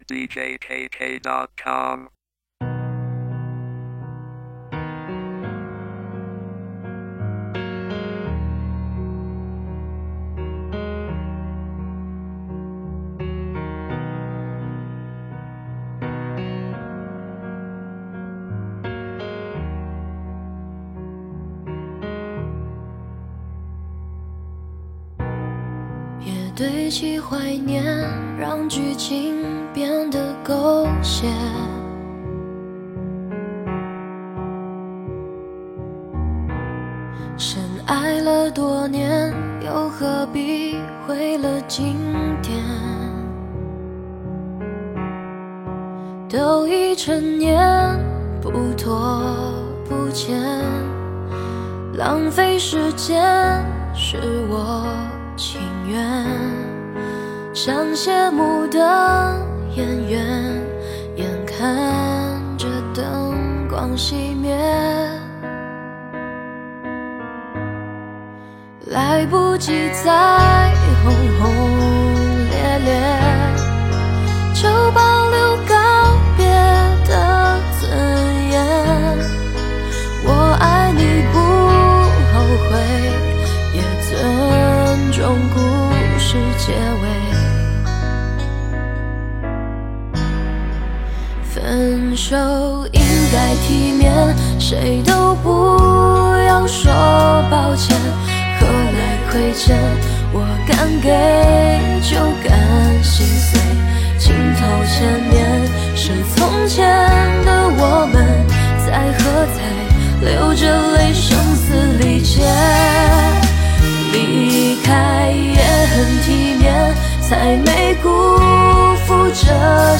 54分钟2018网络流行音乐分享~
DJ